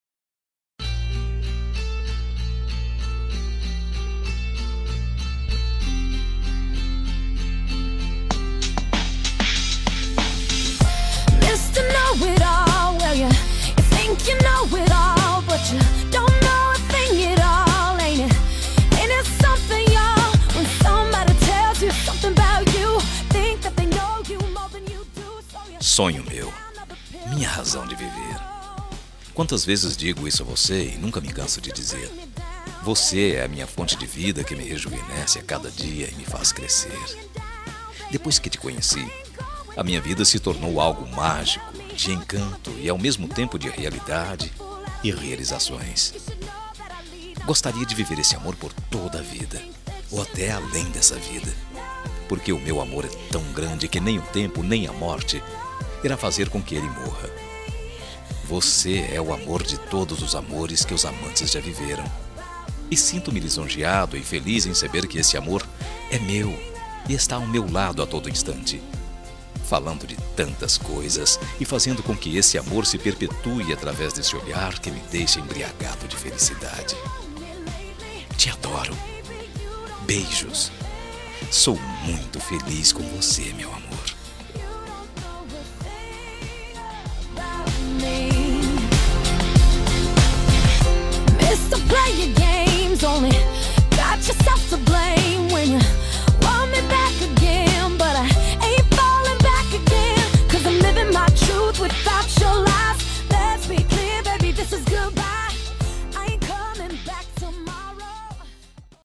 Telemensagem Romântica para Esposa – Voz Masculina – Cód: 202010